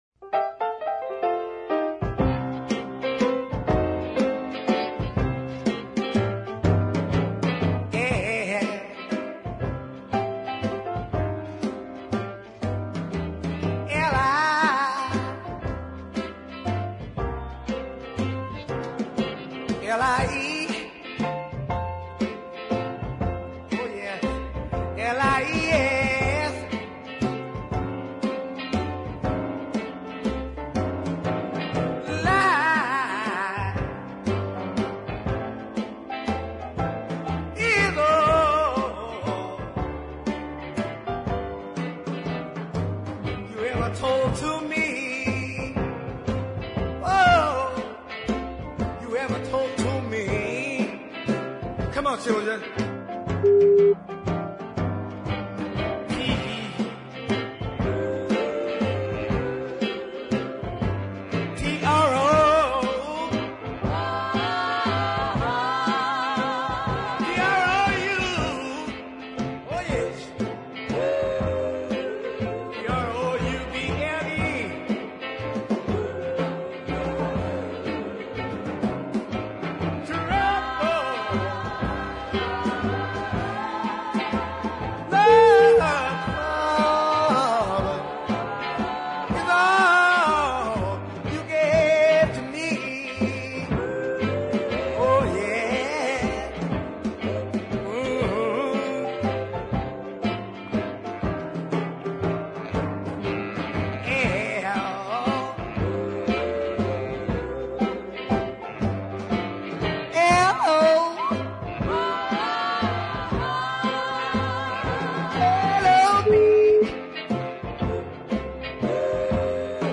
odd-ball deep ballad waltz
with some very nice Louisiana piano
gritty vocal
some righteous female assistants and a big horn section